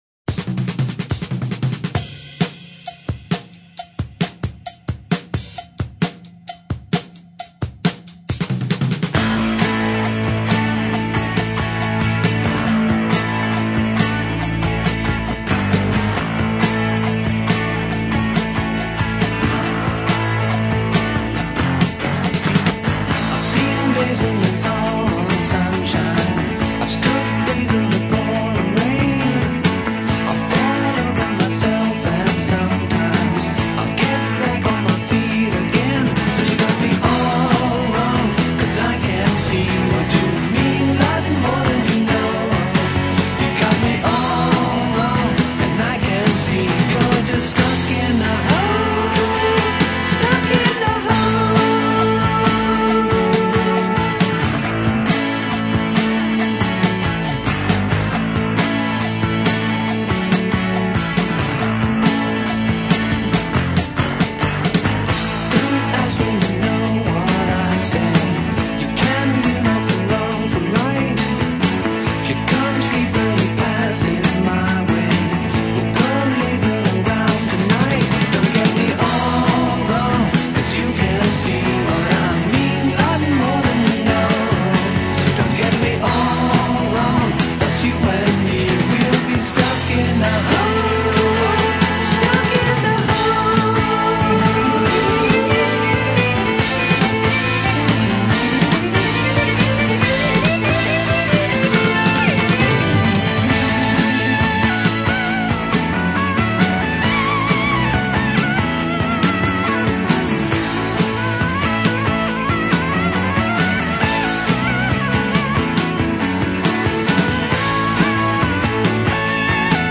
Rock progrssif